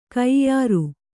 ♪ kaiyāru